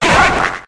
Cri de Palarticho dans Pokémon HOME.